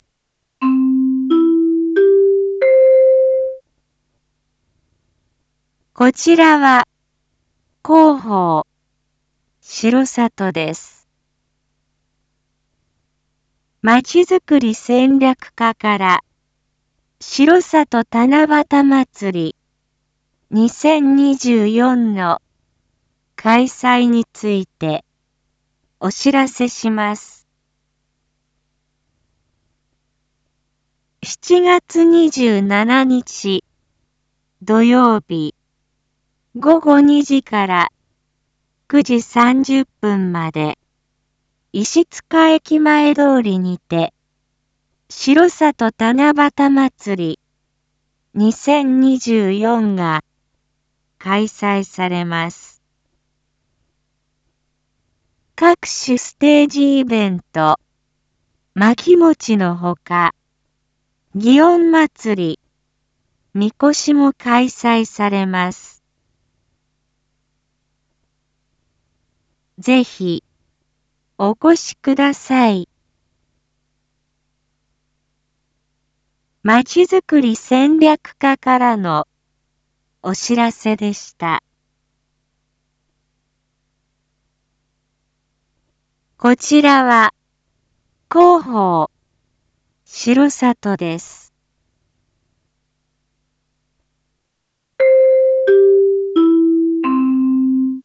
Back Home 一般放送情報 音声放送 再生 一般放送情報 登録日時：2024-07-24 19:01:39 タイトル：しろさと七夕まつり2024開催のお知らせ① インフォメーション：こちらは広報しろさとです。